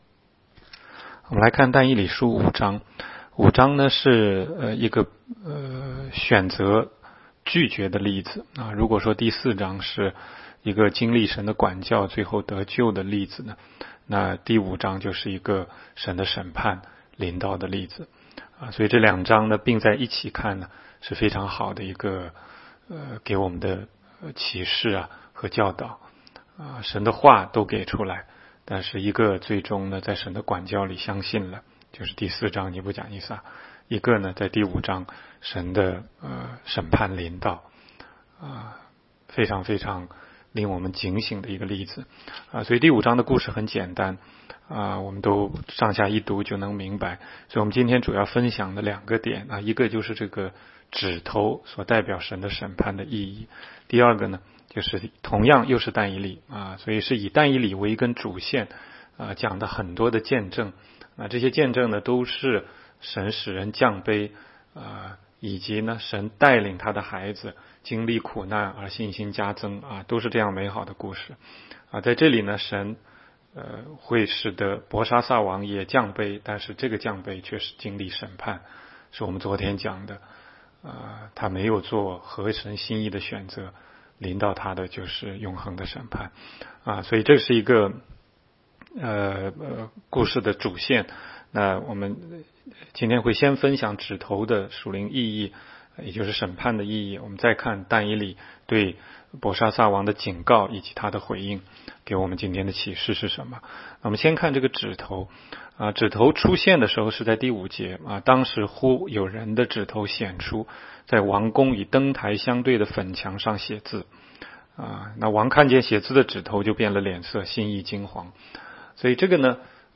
16街讲道录音 - 每日读经 -《但以理书》5章